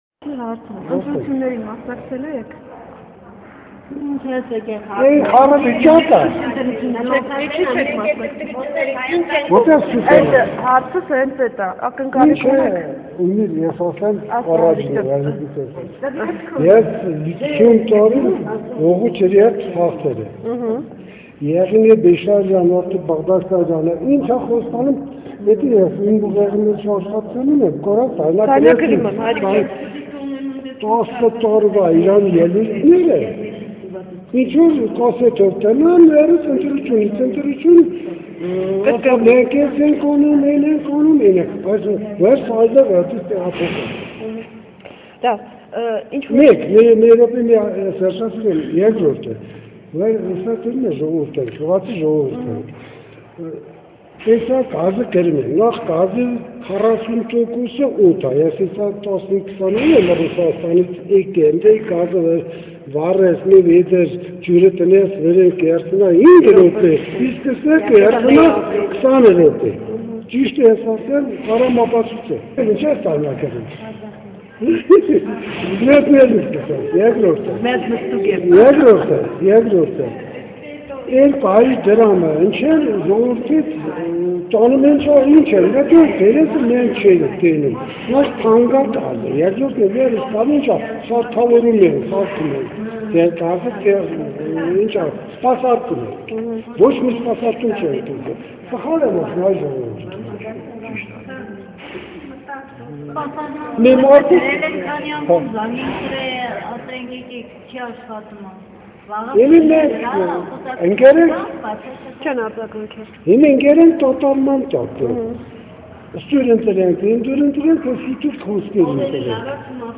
Գավառի տարածաշրջանի գյուղերի մի շարք բնակիչներ պատասխանում են «Առավոտի» հարցերին՝ ընտրություններին մասնակցելու՞ եք, ձեր քվեն կփոխարինե՞ ք ընտրակաշառքով:
Հարցմանը մասնակցեցին մի շարք բնակիչներ՝ 50-65 տարեկան անձինք, որոնք հրաժարվեցին հայտնել իրենց անունները: